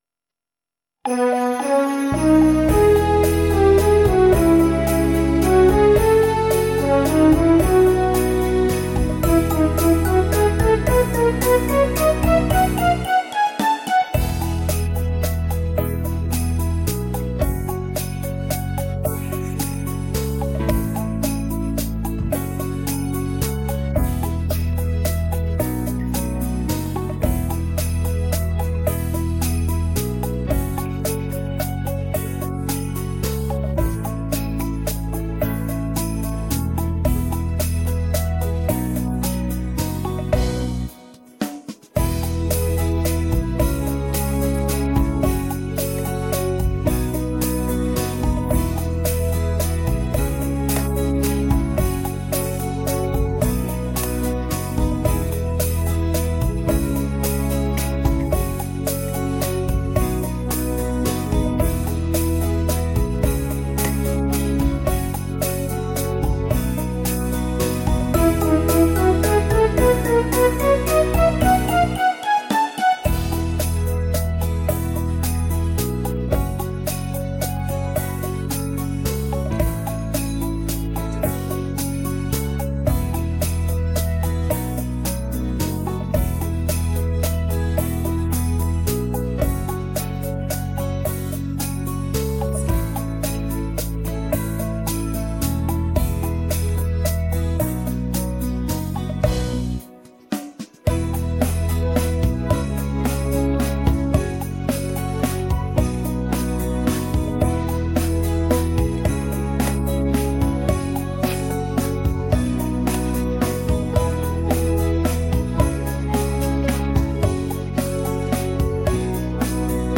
• Категория: Детские песни
Скачать минус детской песни
🎶 Детские песни / Песни на праздник / Песни на Новый год 🎄